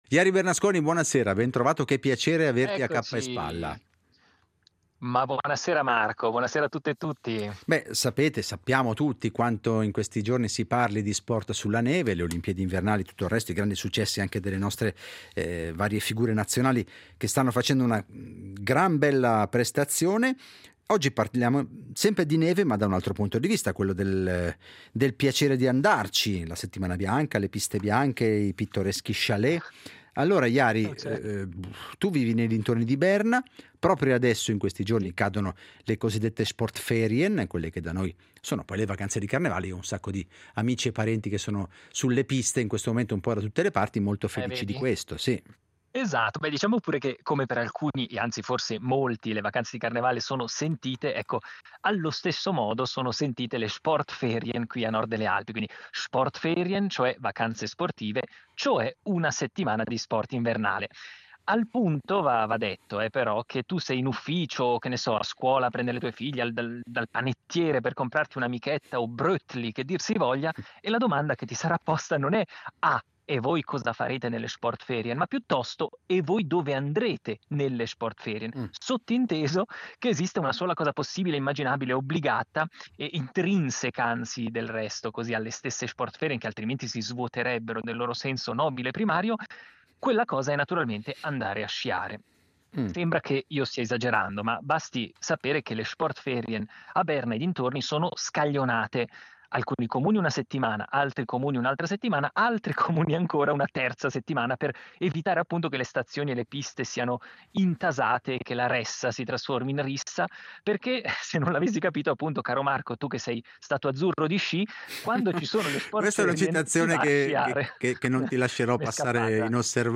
collegato con noi da Berna